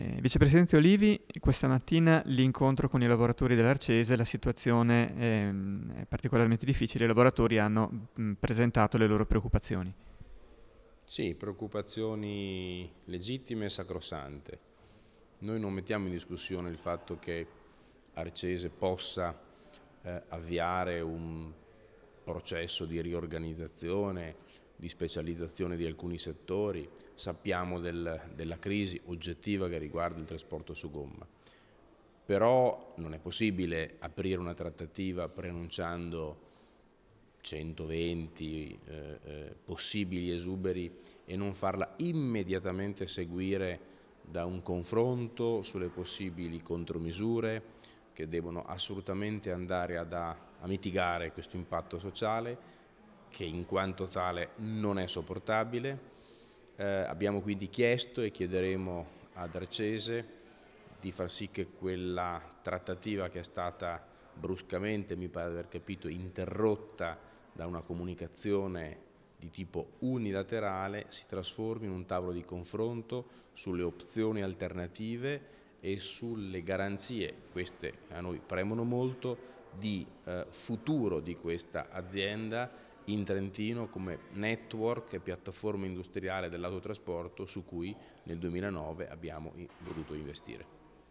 All.: audiointervista vicepresidente Olivi -
audio_intervista_Olivi_su_Arcese.wav